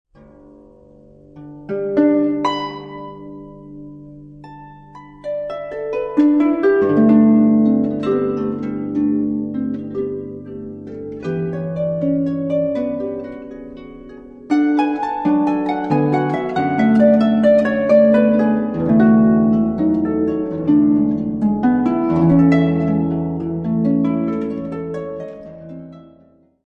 an album of superb Russian solo harp music
modern concert harp